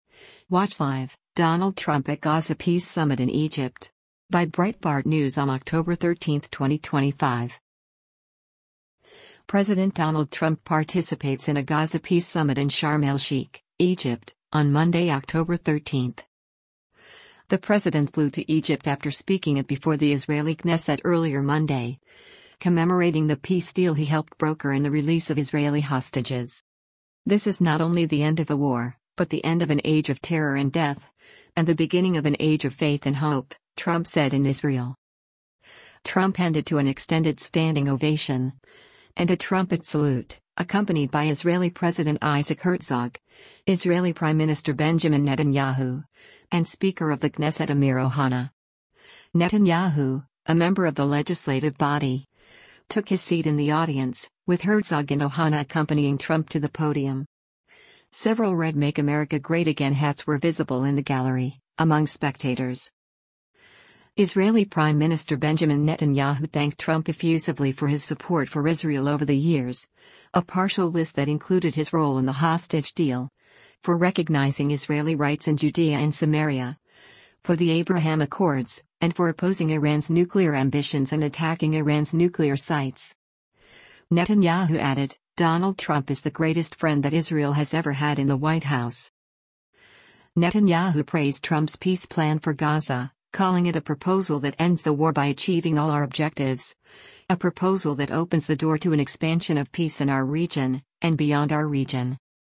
President Donald Trump participates in a Gaza Peace Summit in Sharm El Sheikh, Egypt, on Monday, October 13.